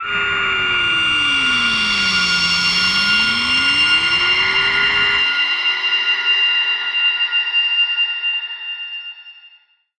G_Crystal-F7-f.wav